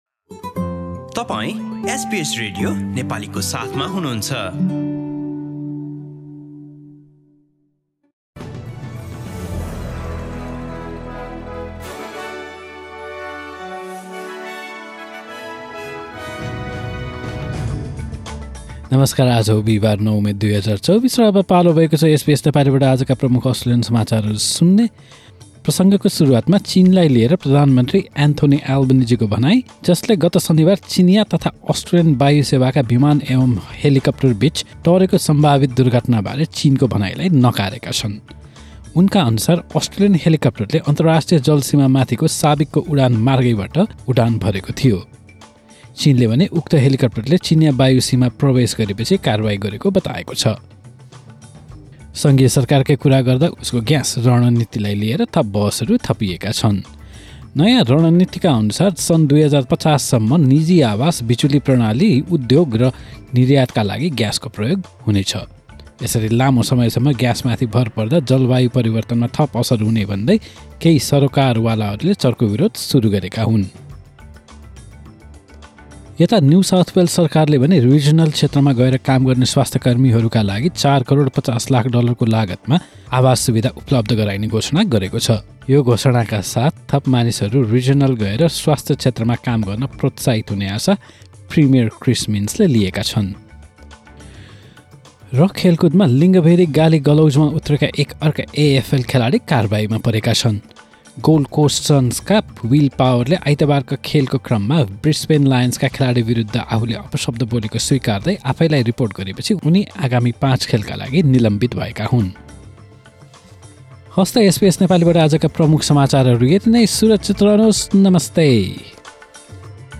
Listen to the latest bitesize top news from Australia in Nepali.